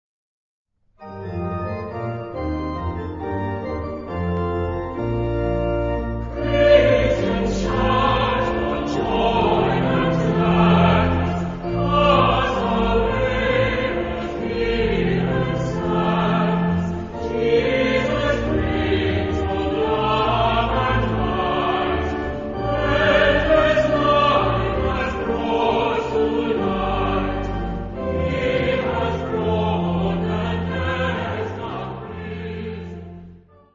Genre-Stil-Form: Motette ; geistlich ; Hymnus (geistlich)
Charakter des Stückes: con brio
Chorgattung: SATB  (4 gemischter Chor Stimmen )
Instrumente: Orgel (1) oder Klavier (1)
Tonart(en): B-dur